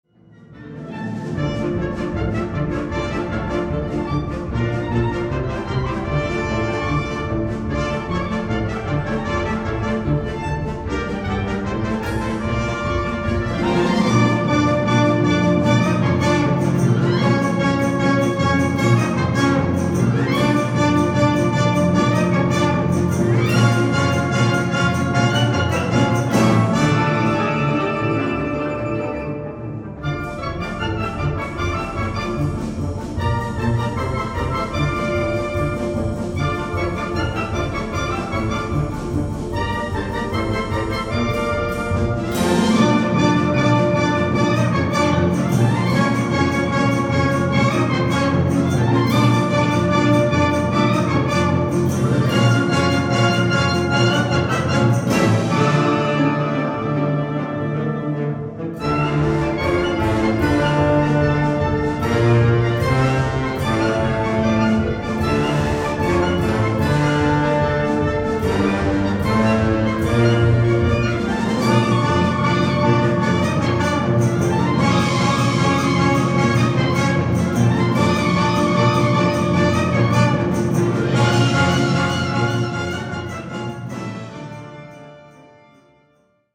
Recueil pour Harmonie/fanfare